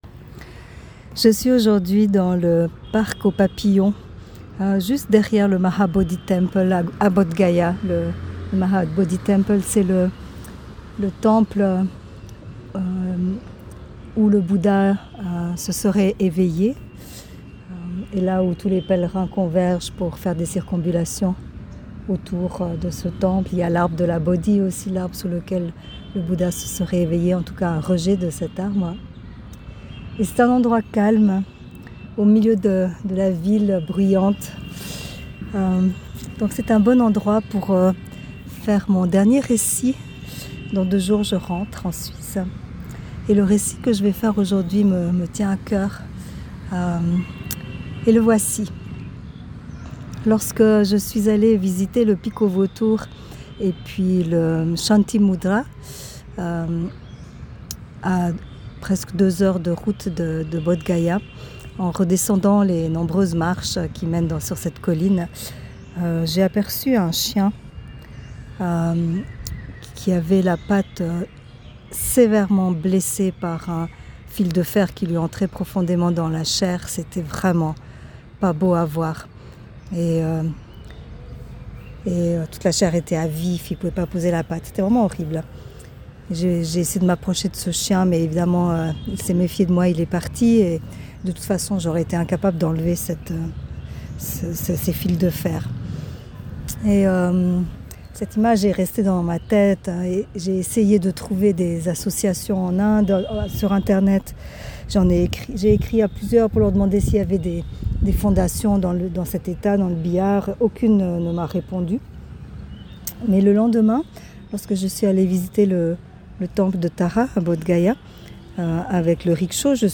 Ses notes vocales capturent l’instant et partagent ses impressions au fil du voyage…